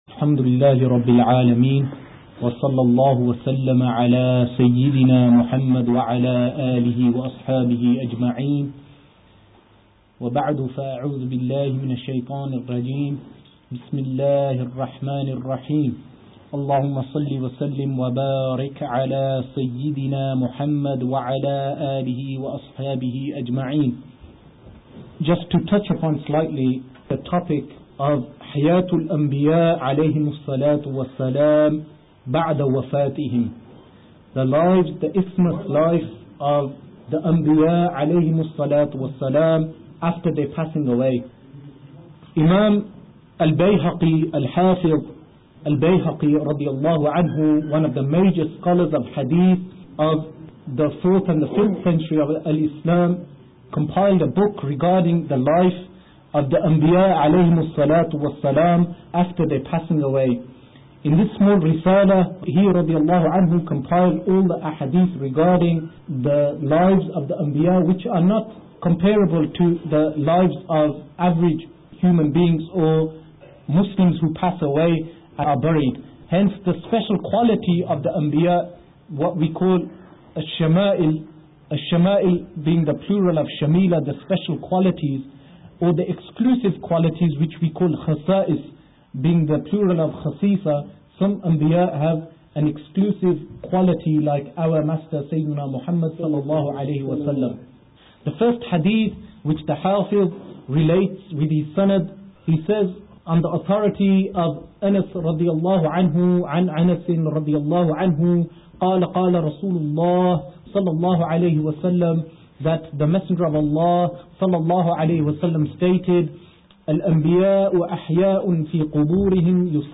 English Speeches Collection The Sahaba are Upright Download Hadith e Qirtaas (Pen and Paper Hadith) Download Do Rullings Change With Time?